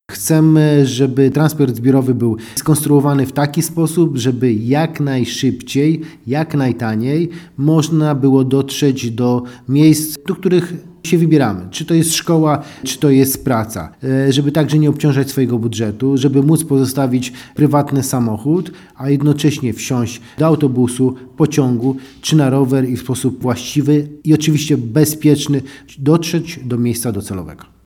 Czemu konsultacje są ważne mówi wiceprezydent Jerzy Zawodnik: